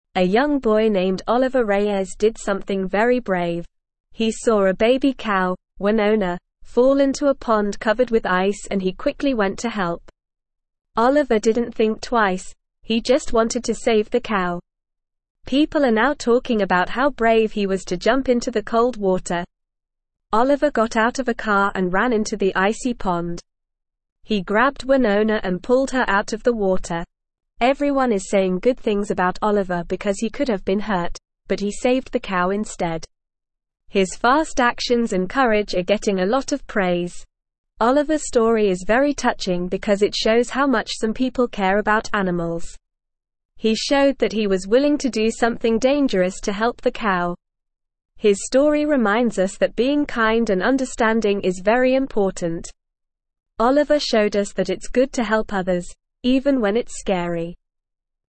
Normal
English-Newsroom-Lower-Intermediate-NORMAL-Reading-Brave-Boy-Saves-Cow-Stuck-in-Cold-Pond.mp3